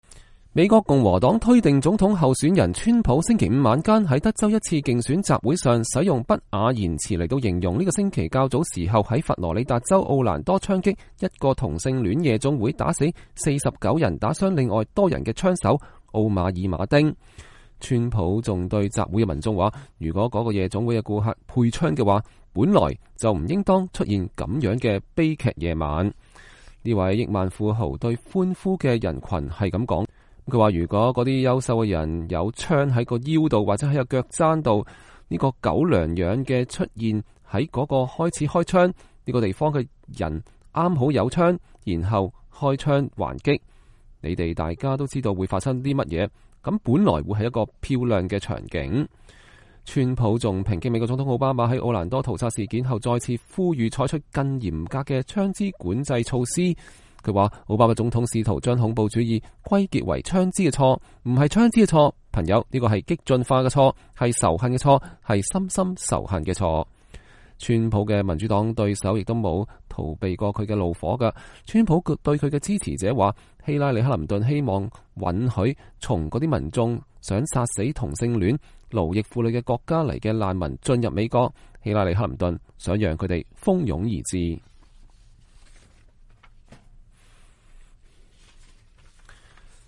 川普還對集會的民眾說，如果該夜總會的顧客配槍的話，本來不應當出現那樣的悲劇夜晚。